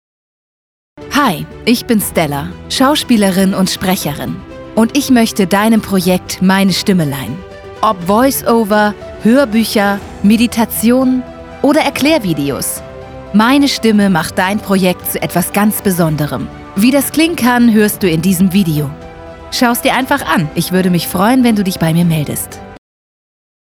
Démo commerciale
ContraltoHaute